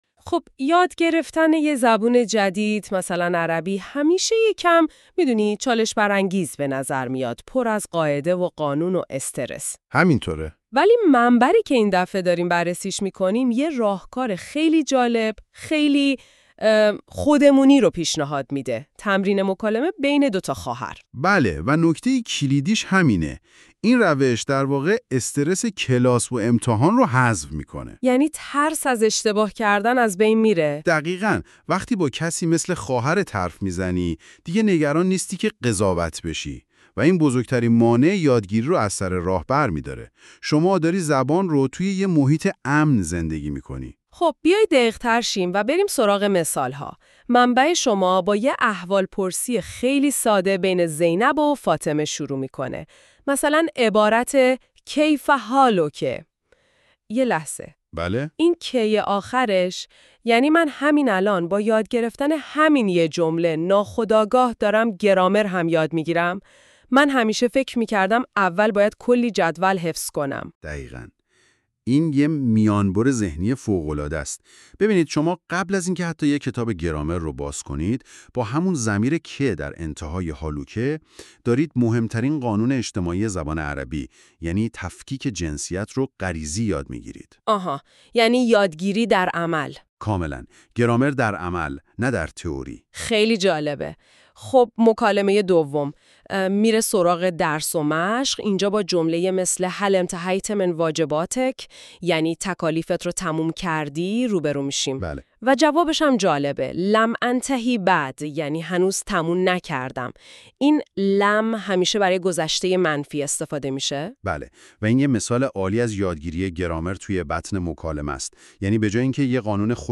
arabic-conversation-between-two-sisters.mp3